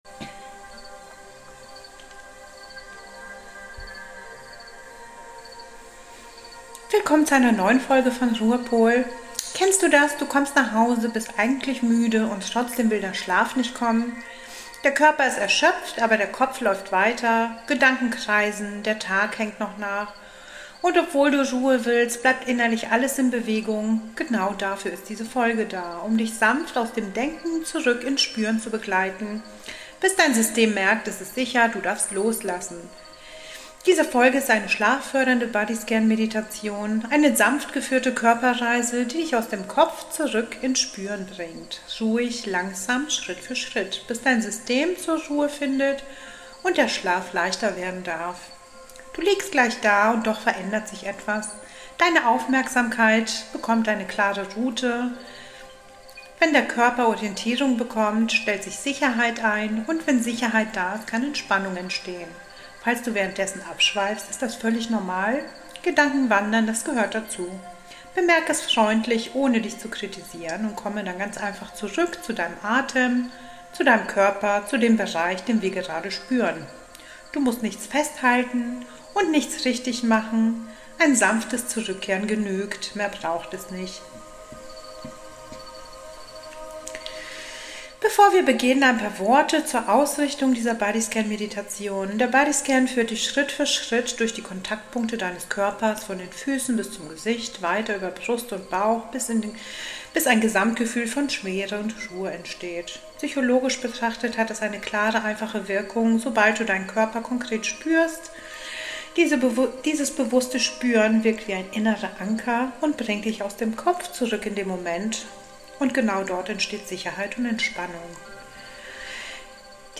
Body Scan zum Einschlafen